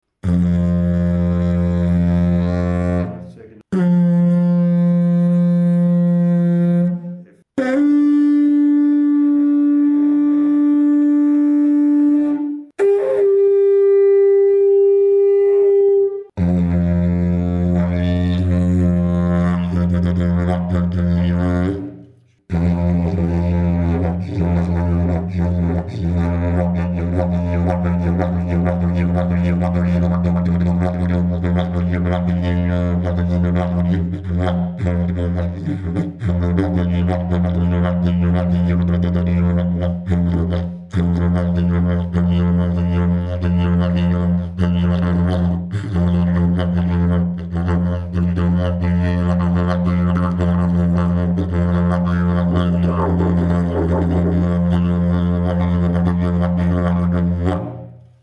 Dg535 ist ein Didgeridoo meines Modells 050 gestimmt in F2, mit dem Overblow sehr genauauf der Oktave F3. Der sehr stabile und leicht anzuspielende Grundton hat sehr effektiven Resonanzwiderstand.
Die Form erzeugt einen nicht so stark dröhnenden Grundton, der sich gut mit Stimmeffekten und rascher Rhythmik modulieren lässt und dadurch auch Mikrophone nicht so leicht übersteuert. Die drei ersten Overblows liegen sehr schön in der vorberechneten Stimmlage F2/F3/D4/G4.
Fundamental tone, draw ranges and overblows at 24� C, concert pitch A=440Hz: F2 � 10 (E2 to F2 + 5) // F# � 0 (-30, + 10) / D4 - 10 (-30, + 0) / G4 + 20 Dg535 Technical sound sample 01